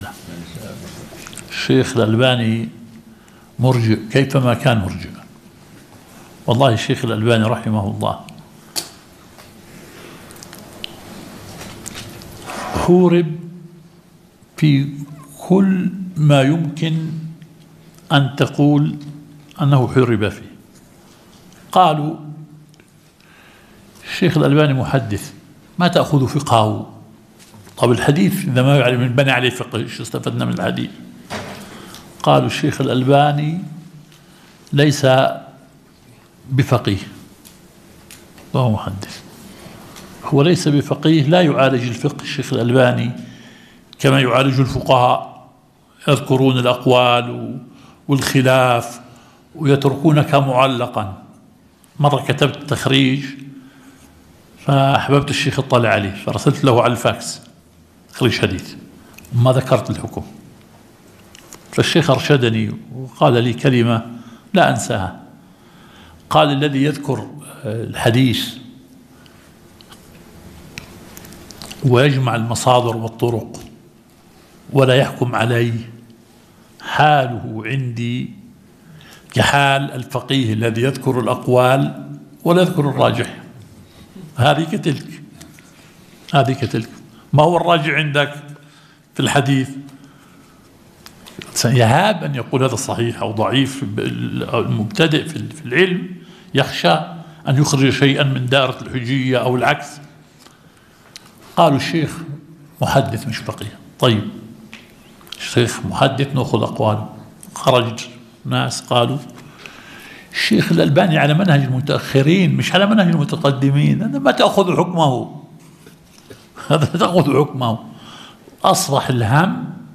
الدرس الثالث – شرح مبحث المطلق والمقيد في أصول الفقه